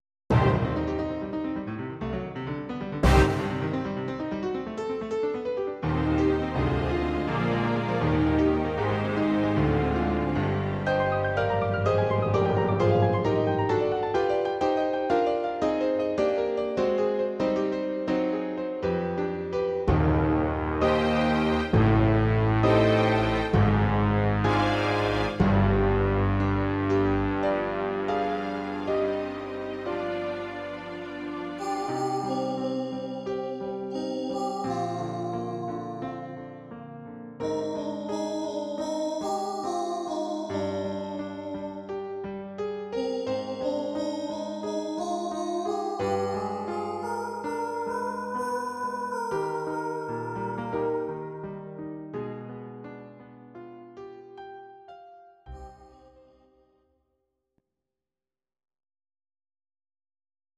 Audio Recordings based on Midi-files
Pop, Ital/French/Span, 2000s